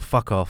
Voice Lines / Dismissive
fuck off.wav